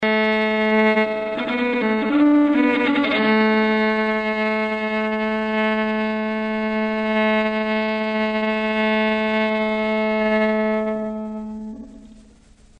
Here, slowed down further and again reduced in pitch, it can be auditioned in detail: